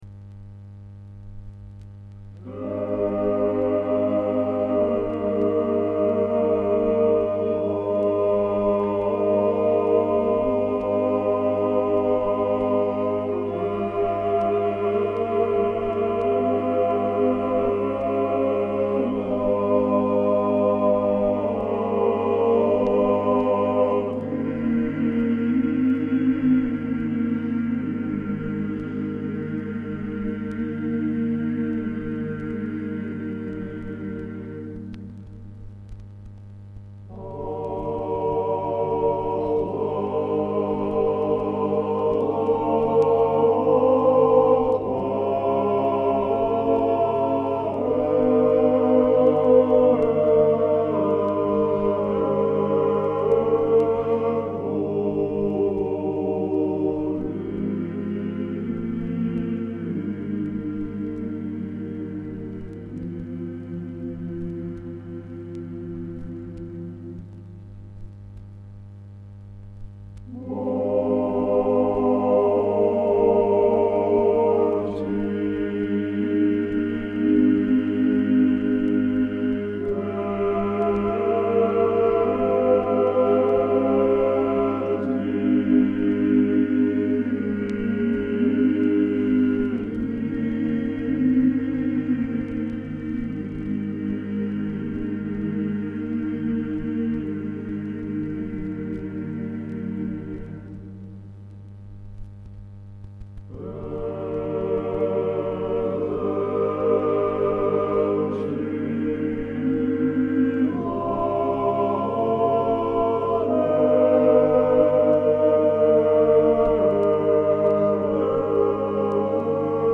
სხვა სათაური: Грузинские церковные песнопения
A Georgian congregational song
საკვანძო სიტყვები: ქართული ხალხური სიმღერა